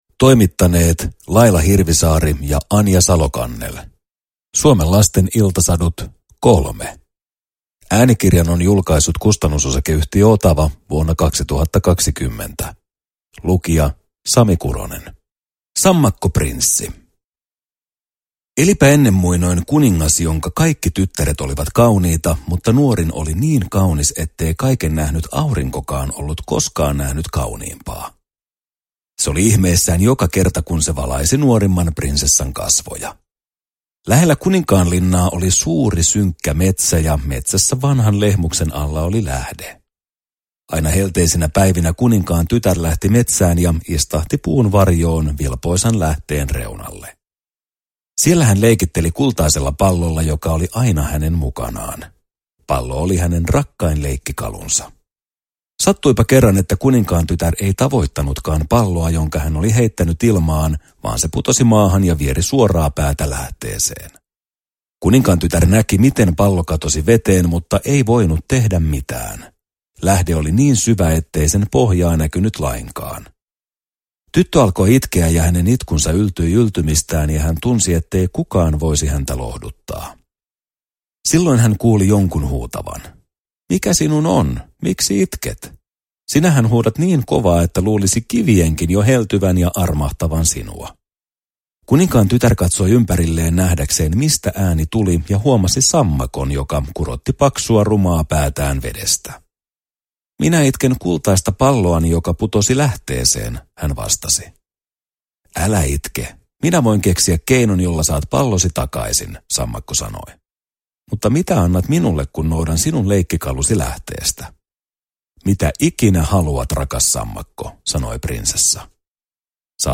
Suomen lasten iltasadut 3 – Ljudbok – Laddas ner